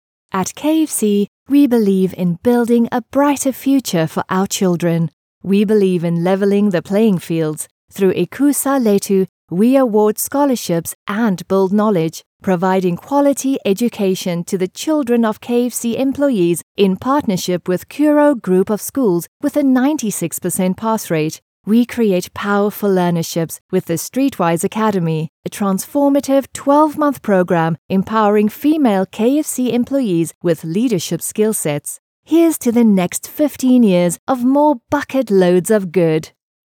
Anglais (sud-africain)
Approachable Believable Warm